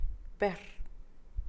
per[pehr]